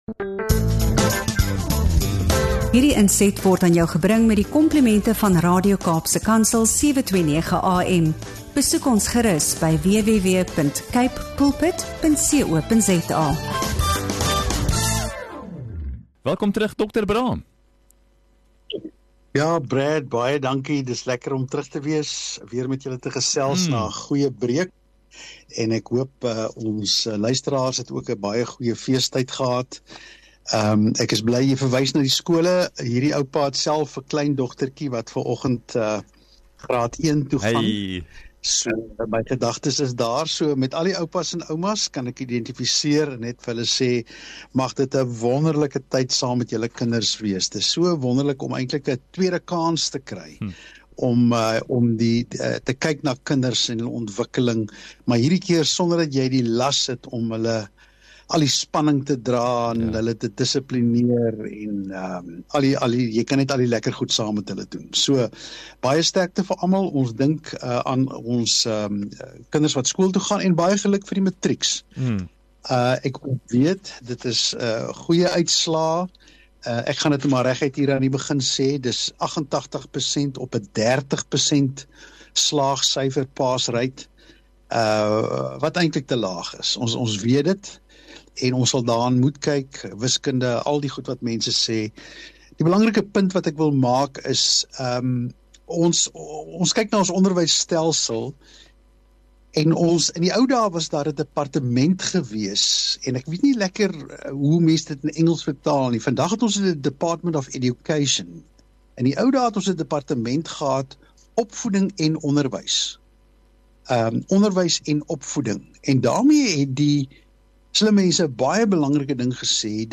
Die gesprek beweeg verder na rentmeesterskap vanuit ’n Bybelse perspektief: aan wie behoort die land regtig, en wat beteken dit vir grond, politiek en mag?